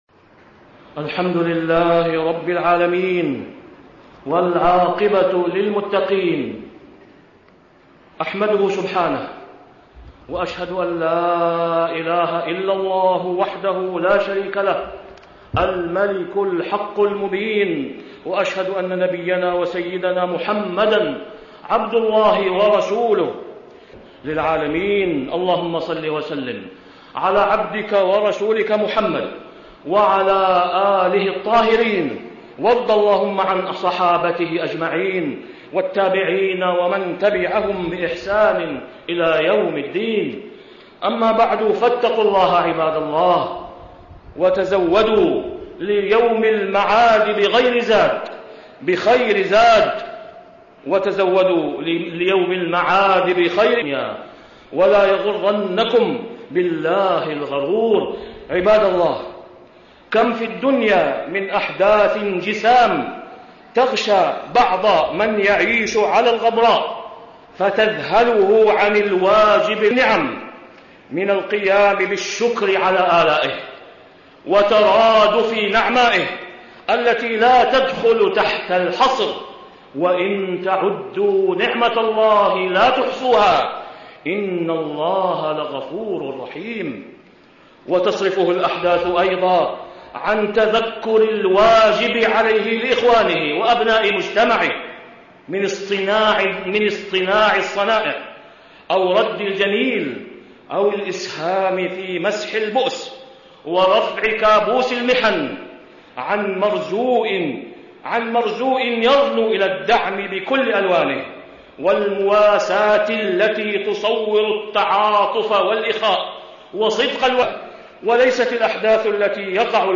تاريخ النشر ٢٤ رجب ١٤٣٥ هـ المكان: المسجد الحرام الشيخ: فضيلة الشيخ د. أسامة بن عبدالله خياط فضيلة الشيخ د. أسامة بن عبدالله خياط الفراغ بين الإهمال والشكر